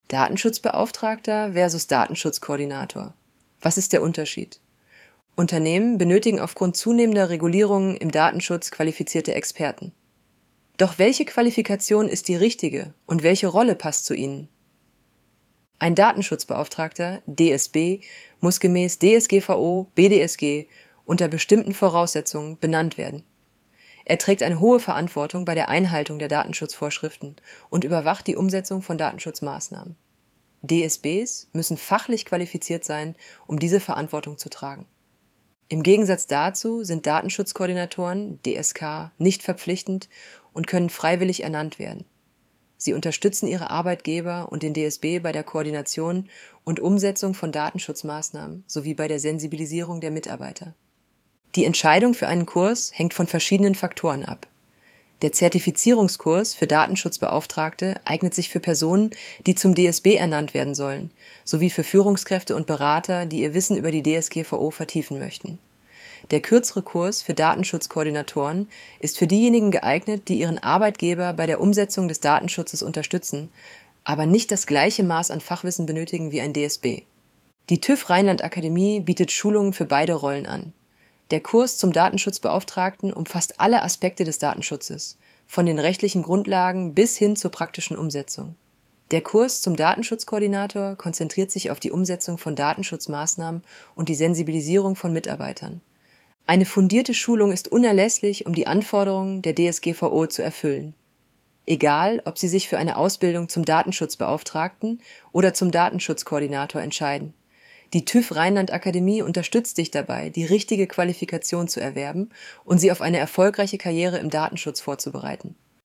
Hinweis: Diese Vorlesefunktion verwendet eine synthetisch erzeugte Stimme aus einem KI-System.Die Stimme ist keine Aufnahme einer realen Person.